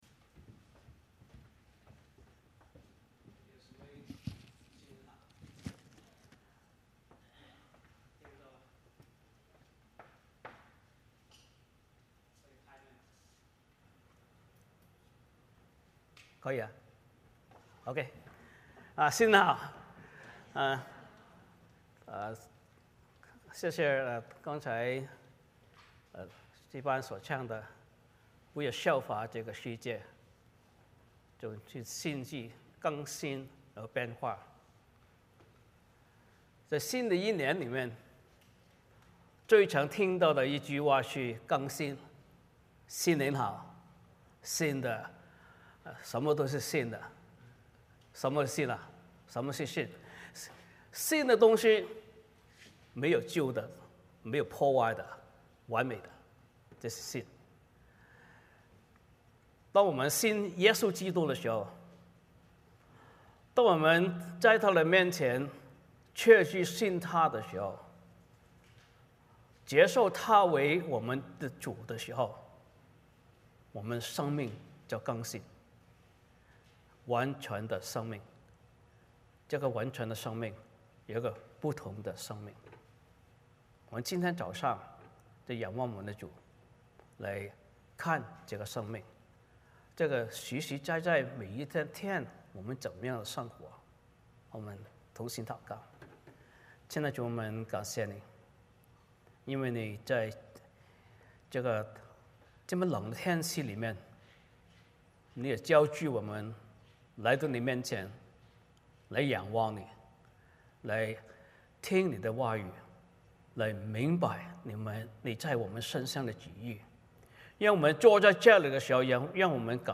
欢迎大家加入我们国语主日崇拜。